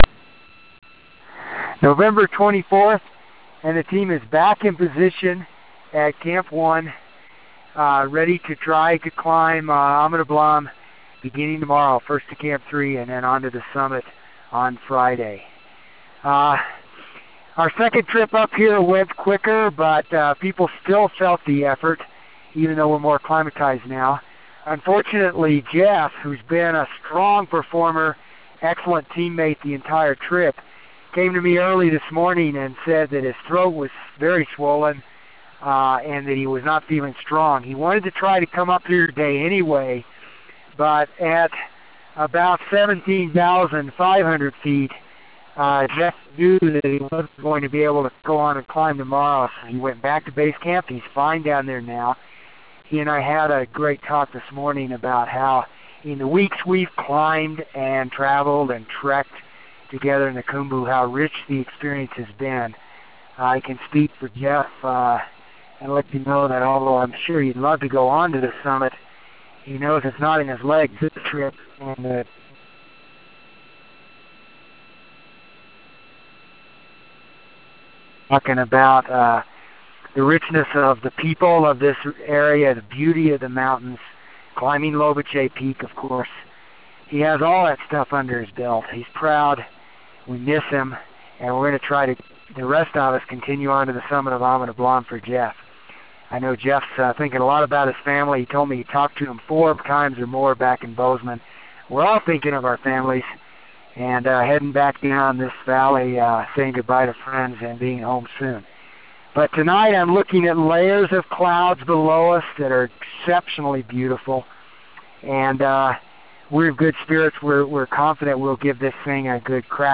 November 24 - Thinking of our Families from Camp 1